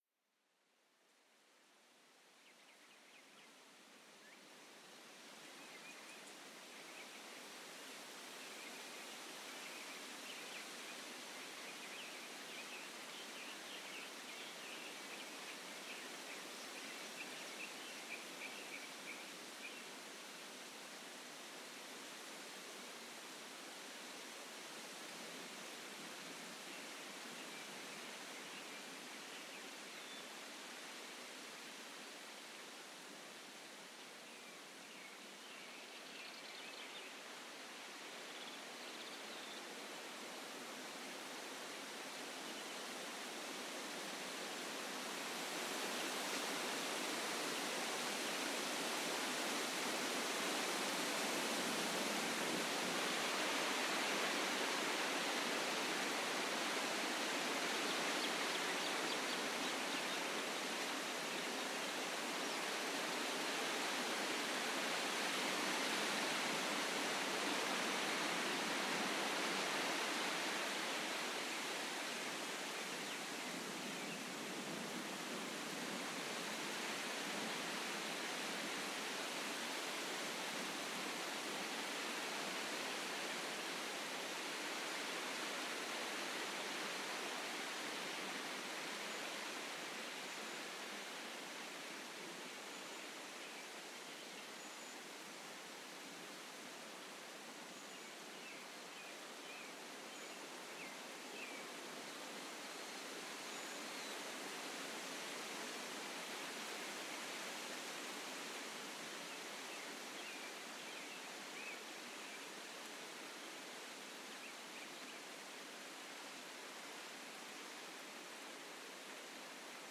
Звуки летнего дня на ферме (без животных)